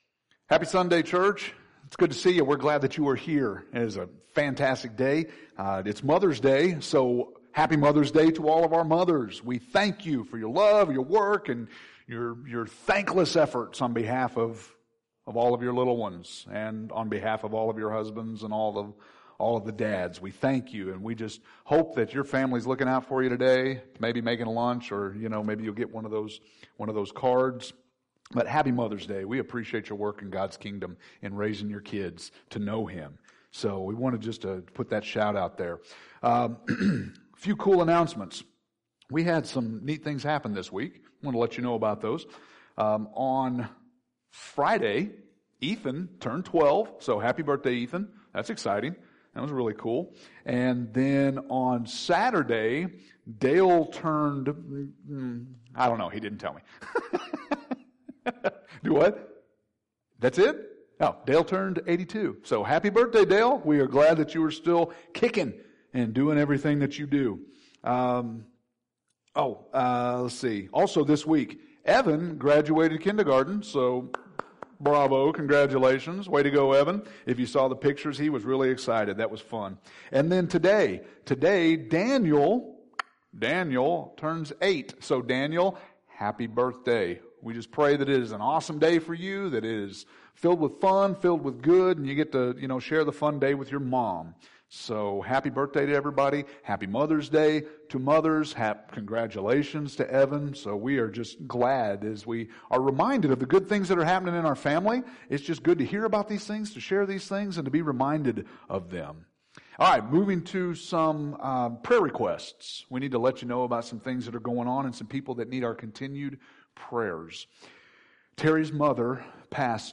May 10th – Sermons